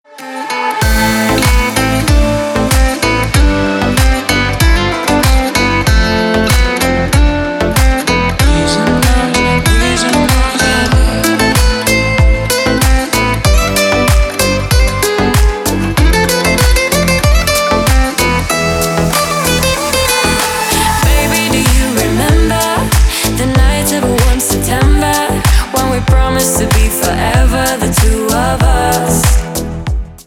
• Качество: Хорошее
• Песня: Рингтон, нарезка